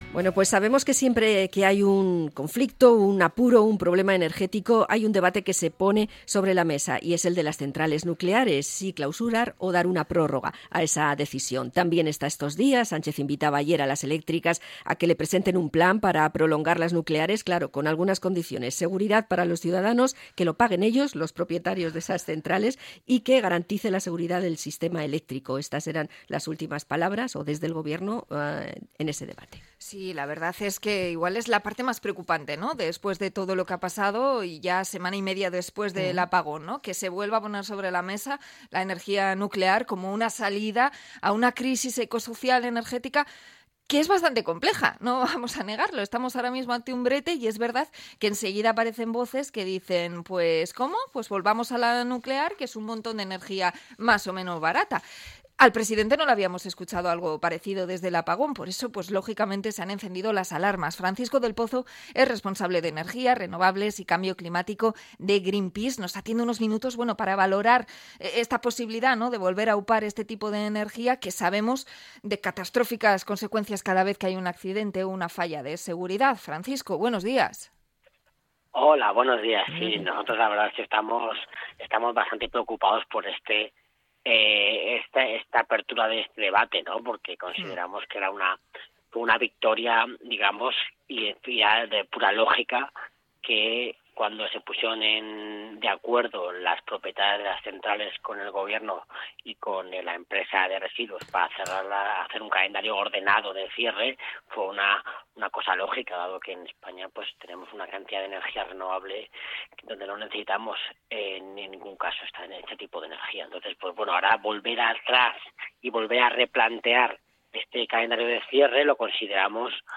Entrevista a Greenpeace por las declaraciones de Pedro Sánchez sobre las centrales nucleares